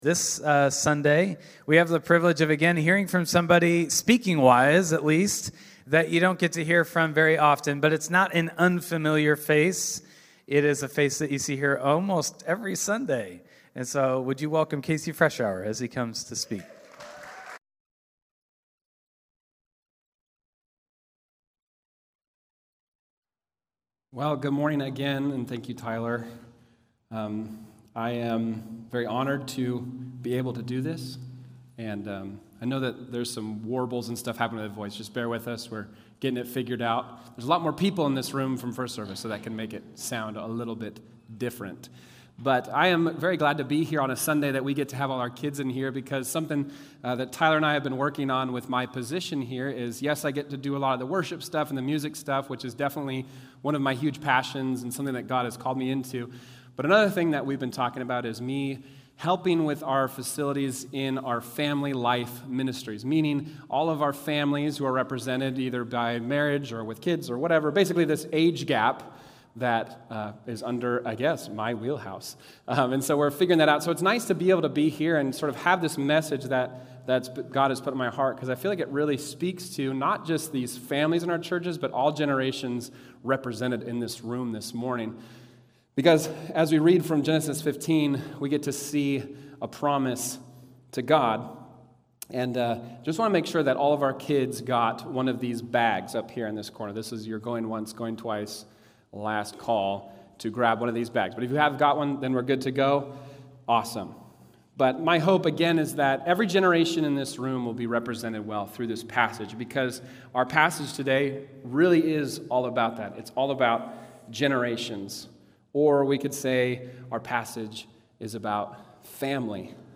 Genesis 15:1-6 (2025 Stand Alone Sermon)